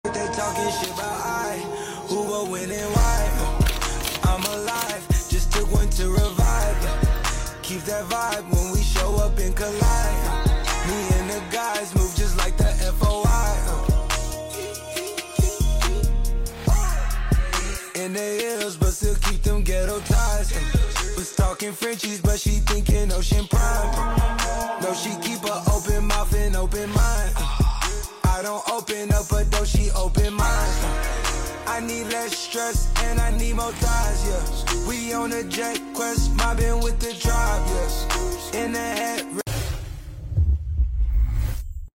Scissor Work / Cutting Shears ✂✨